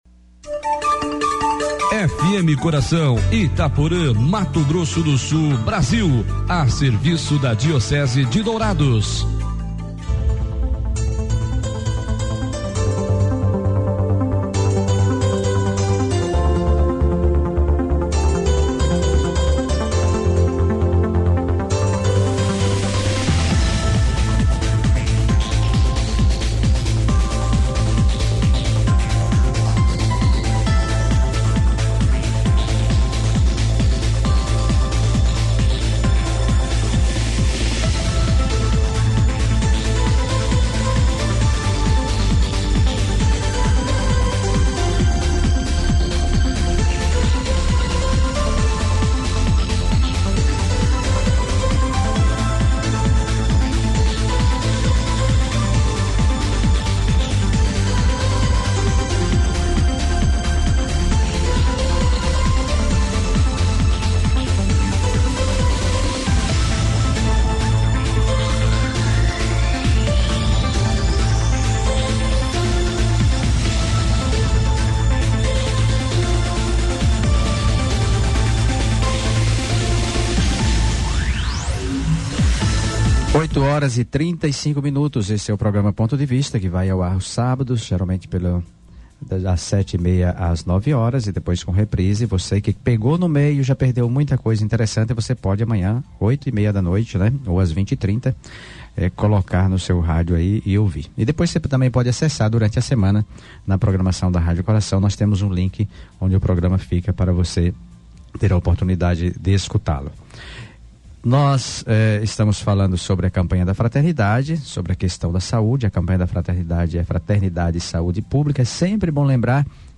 Entrevistados